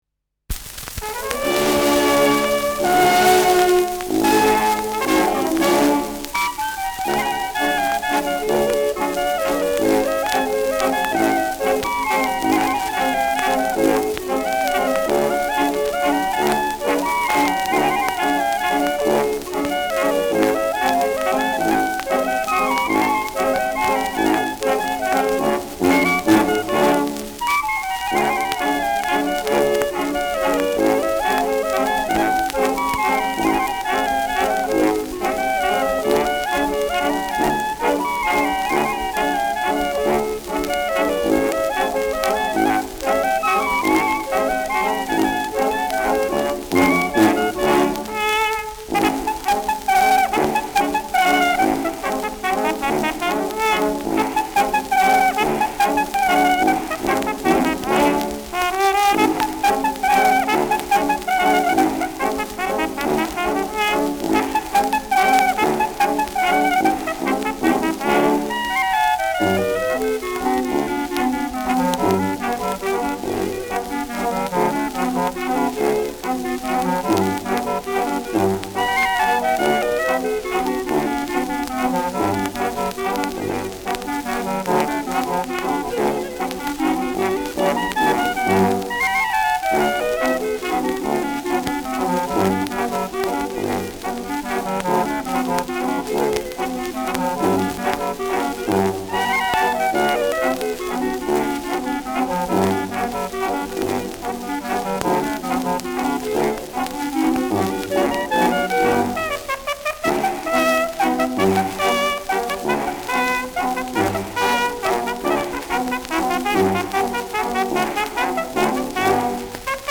Schellackplatte
Tonrille: Abrieb : graue Rillen : Kratzer 3 / 6 / 9 Uhr
präsentes Rauschen